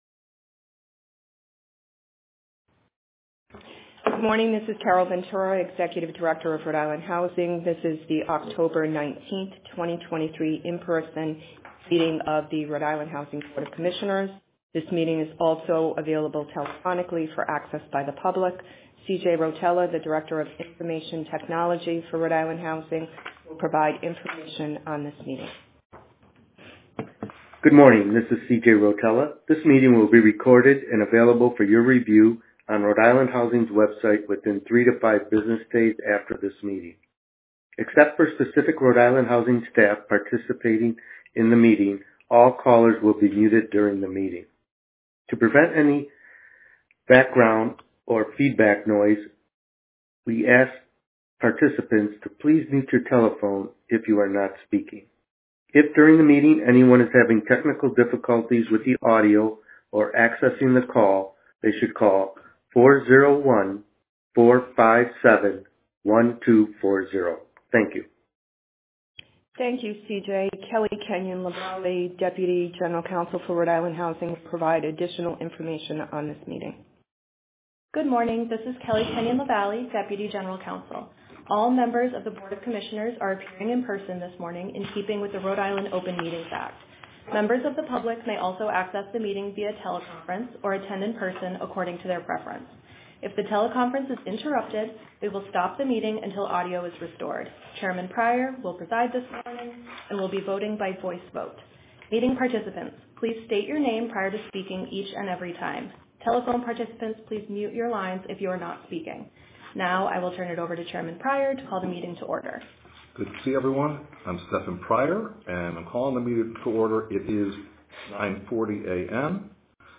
Recording of RIHousing Board of Commissioners Meeting: 10.19.2023